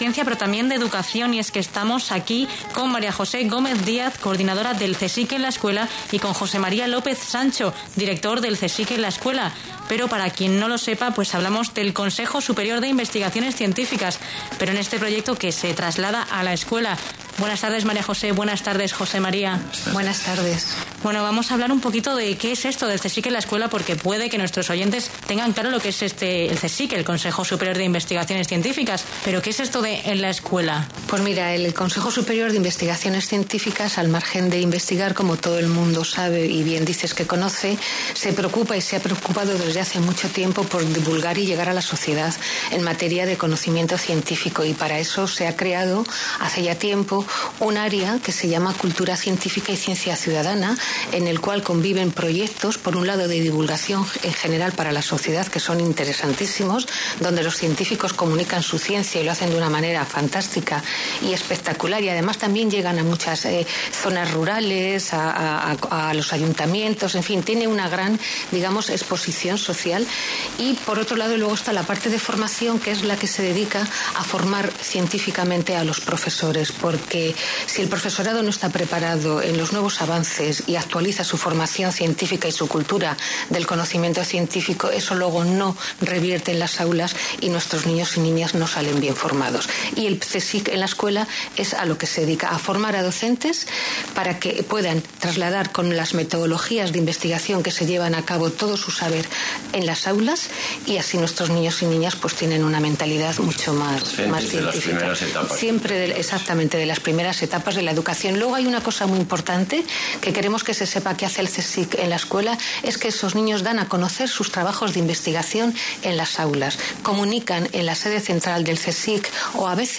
entrevista-SER-melilla.mp3